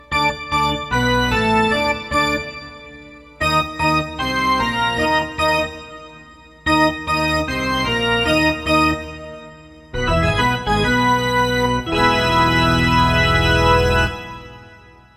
Charge - Baseball Organ
charge-baseball-organ.mp3